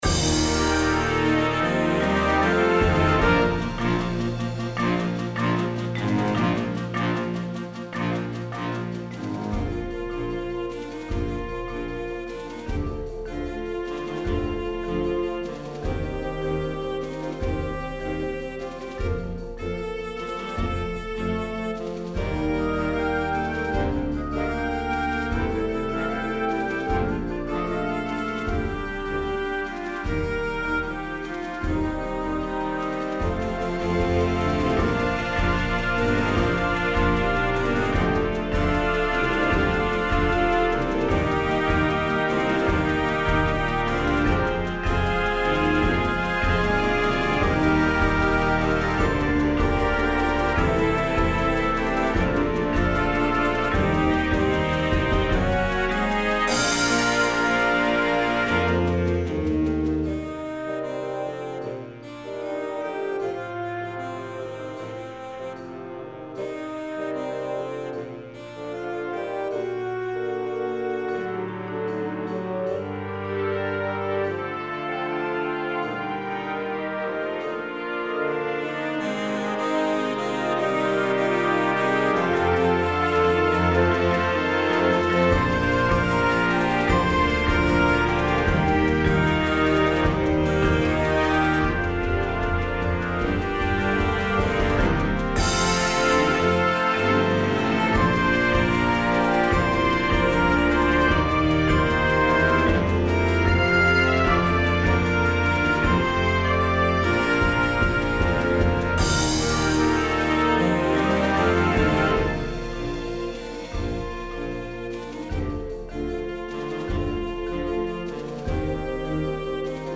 Concert Band
Woodwinds
Brass
Percussion
Flute 1,2
Oboe 1,2
Bb Clarinet 1,2,3
Bass Clarinet
Saxophones (AATB)
Bassoon 1,2
Bb Trumpet 1,2
F Horn 1,2
Trombone 1,2
Euphonium
Tuba
Glockenspiel
Marimba
Snare Drum
Bass Drum
Tambourine
Crash Cymbal
Suspended Cymbal